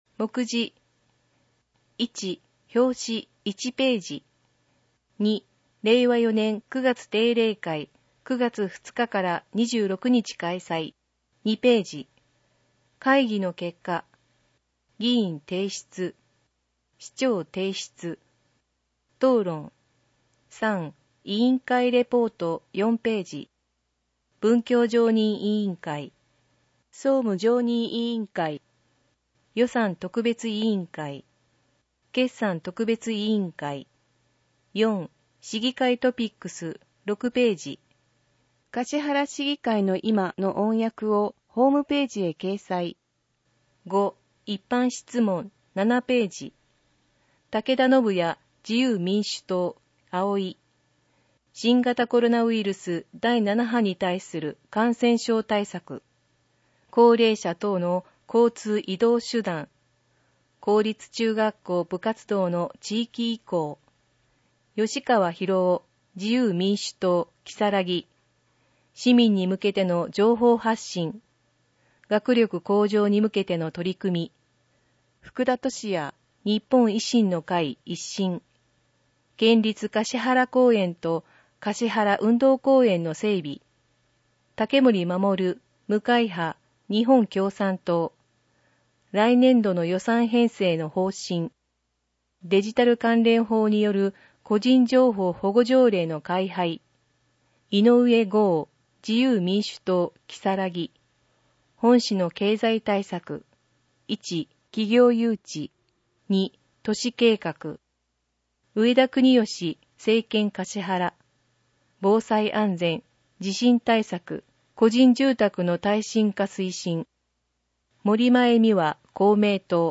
音訳データ
かしはら市議会のいま223号 (PDFファイル: 3.8MB) 音訳データ かしはら市議会のいま第223号の音訳をお聞きいただけます。 音訳データは、音訳グループ「声のしおり」の皆さんが音訳されたものを使用しています。